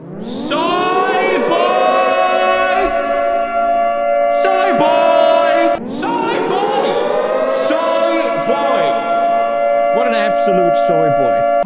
Play PewDiePie Shouting Soyboy - SoundBoardGuy
pewdiepie-shouting-soyboy.mp3